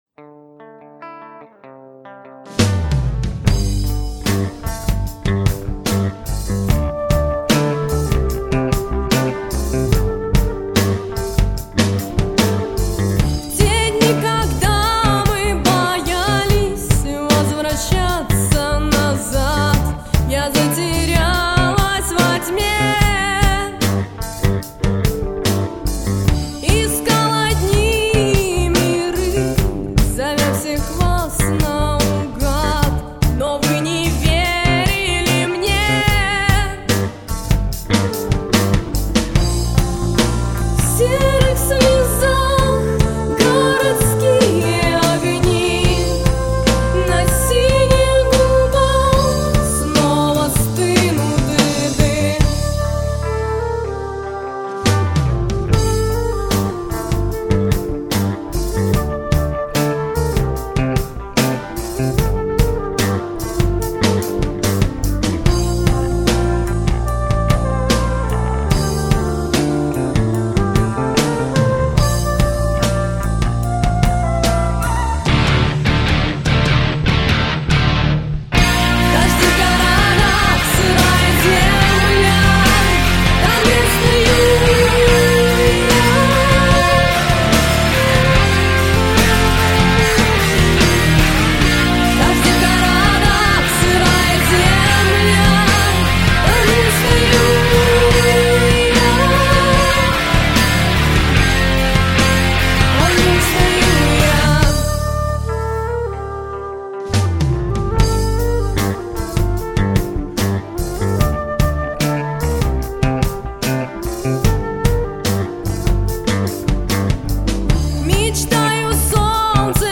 Гитара, вокал, флейта, ударные, бас, клавиши